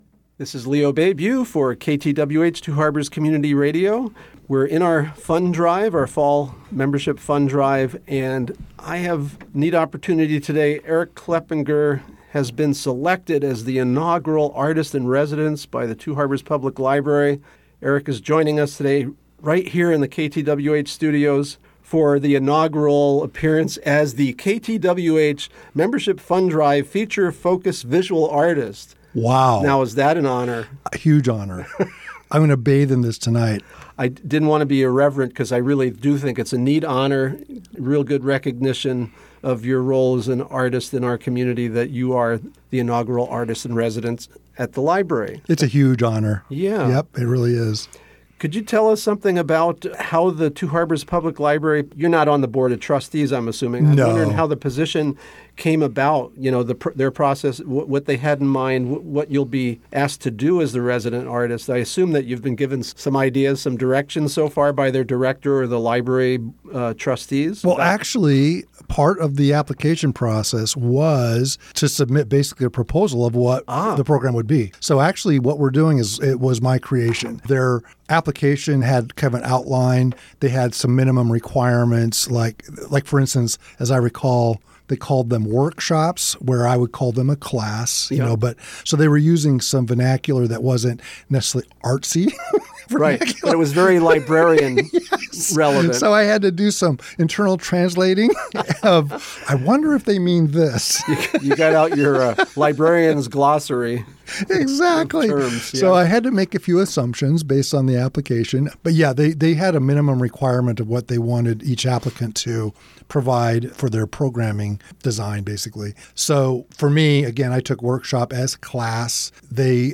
Artist in Residence Interview